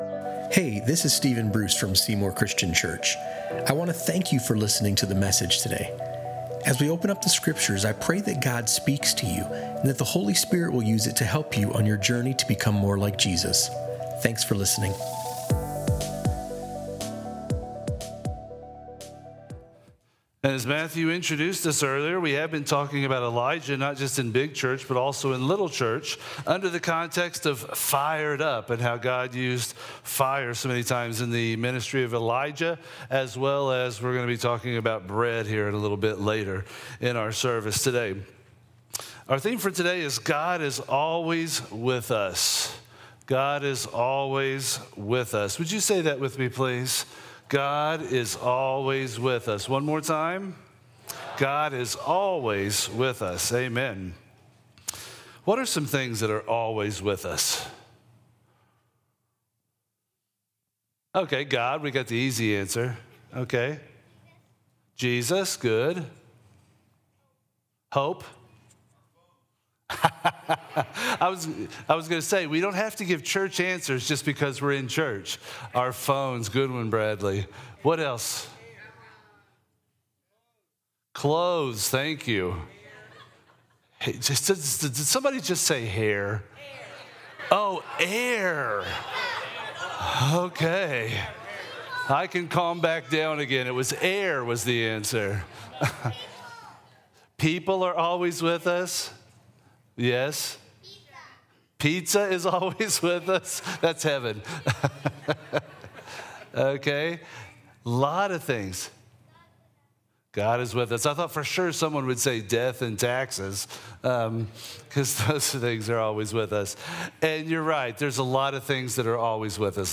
In this engaging and practical message, we follow the powerful journey of Elijah and Elisha.